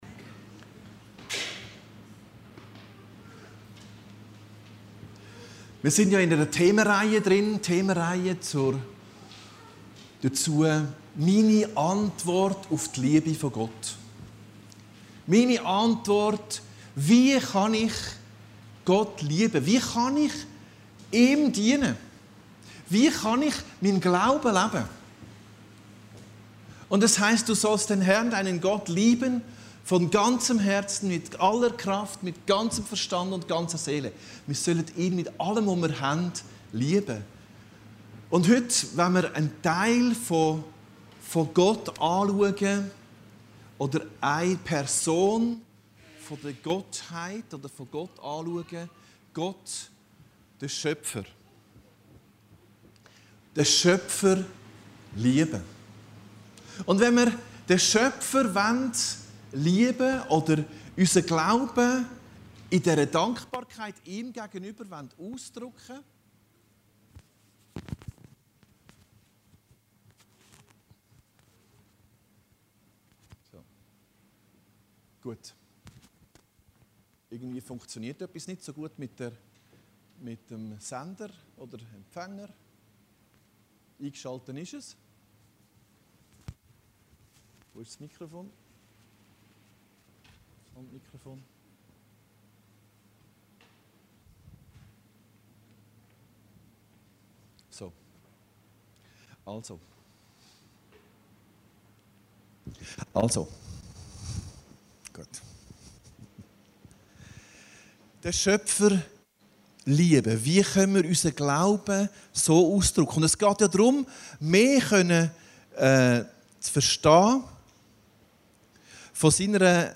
Predigten Heilsarmee Aargau Süd – Meine Antwort: Den Schöpfer lieben